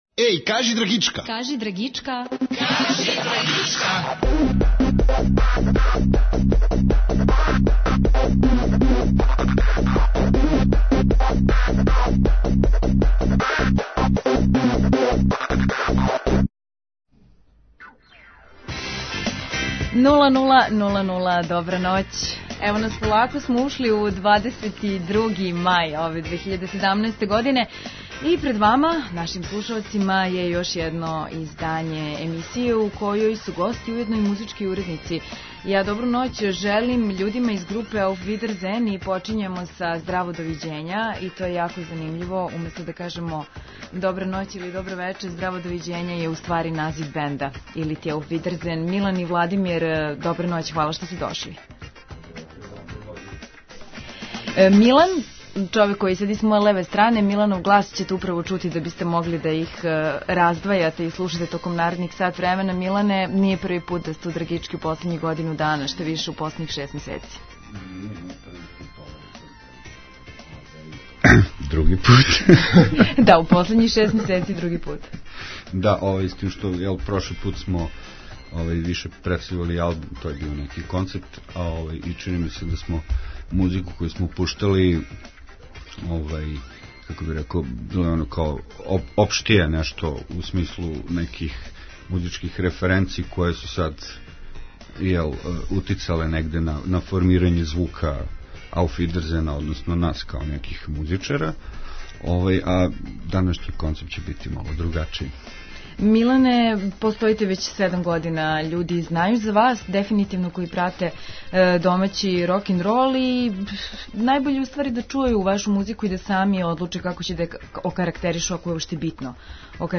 Ноћас музику бирају чланови групе Auf Wiedersehen.